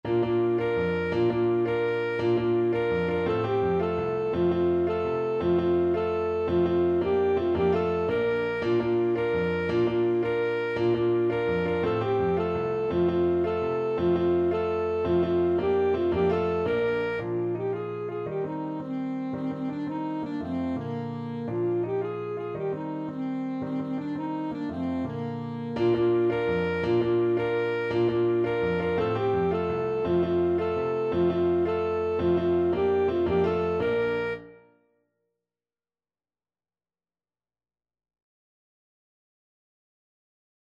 Alto Saxophone
3/4 (View more 3/4 Music)
Bb4-Bb5
Fast and energetic =c.168